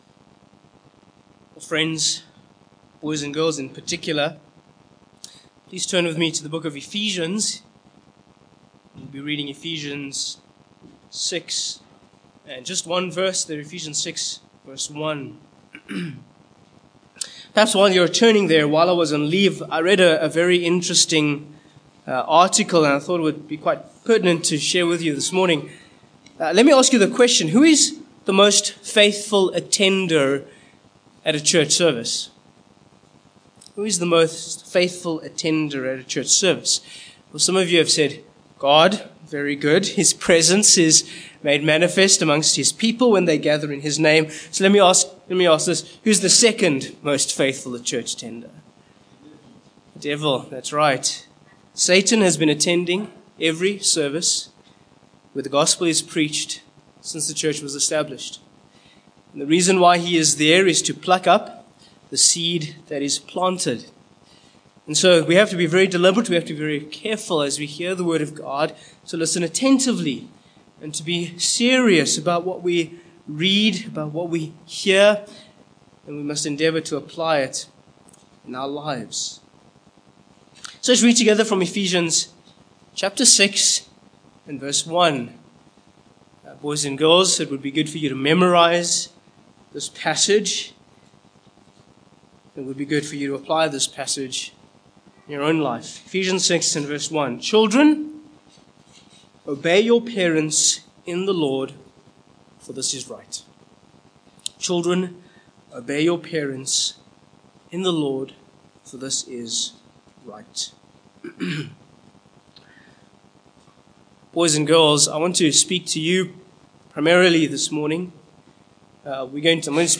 Ephesians 6:1 Service Type: Morning Passage